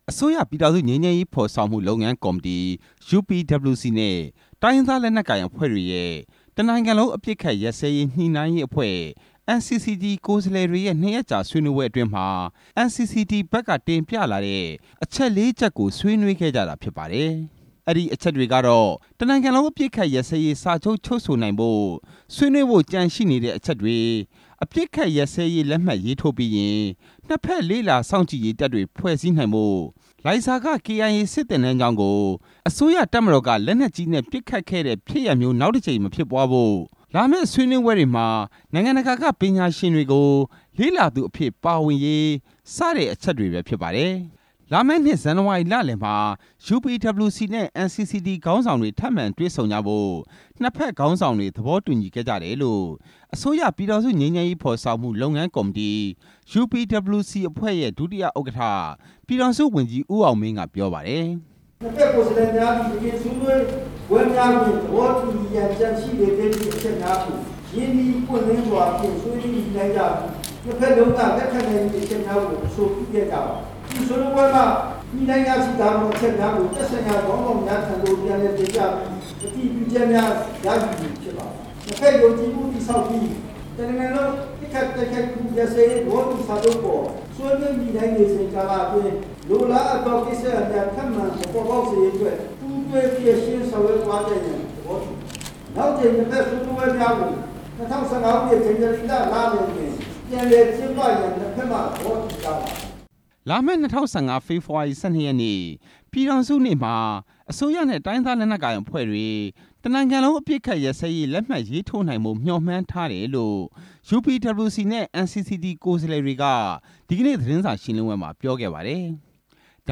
လာမယ့် ၂၀၁၅ ဖေဖဝါရီလ ၁၂ ရက်နေ့ ပြည်ထောင်စုနေ့မှာ အစိုးရနဲ့တိုင်းရင်းသားနက်လက်ကိုင် အဖွဲ့အစည်းတွေ တစ်နိုင်ငံလုံးအပစ်အခတ်ရပ်စဲရေးလက်မှတ်ရေးထိုးနိုင်ဖို့ မျှော်မှန်းထားတယ်လို့ အစိုးရ ပြည်ထောင်စုငြိမ်းချမ်းရေး ဖော်ဆောင်မှုလုပ်ငန်းကော်မတီ UPWC နဲ့ တိုင်းရင်းသားလက်နက်ကိုင်အဖွဲ့တွေရဲ့ တစ်နိုင်ငံလုံးအပစ်ခတ်ရပ်စဲရေး ညှိနှိုင်းရေးအဖွဲ့ NCCT ကိုယ်စားလှယ်တွေက သတင်းစာရှင်းလင်းပွဲမှာ ပြောခဲ့ပါတယ်။